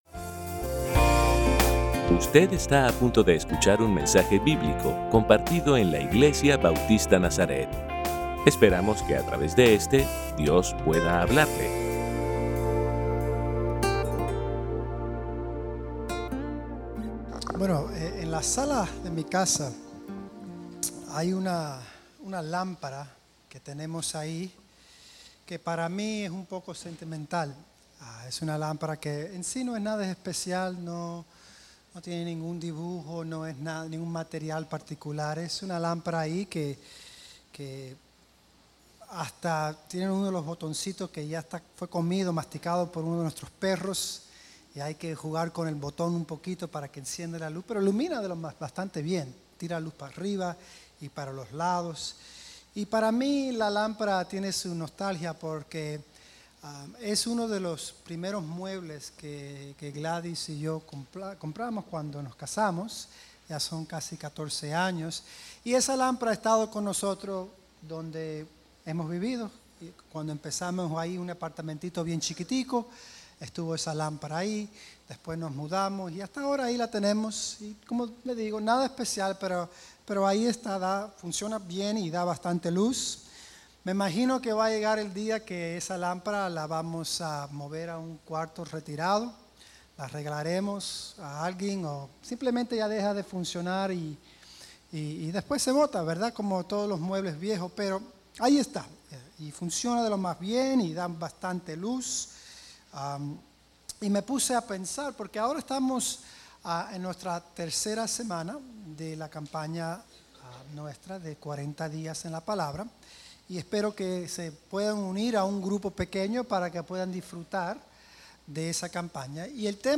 Servicio Dominical